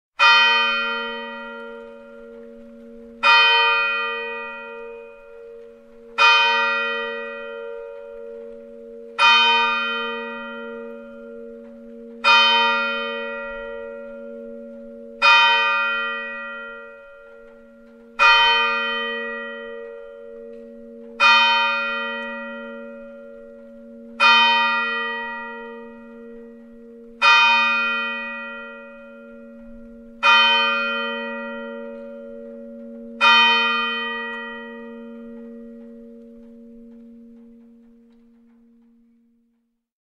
دانلود صدای ناقوس کلیسا 1 از ساعد نیوز با لینک مستقیم و کیفیت بالا
جلوه های صوتی
برچسب: دانلود آهنگ های افکت صوتی اشیاء دانلود آلبوم صدای ناقوس کلیسا – انواع مختلف از افکت صوتی اشیاء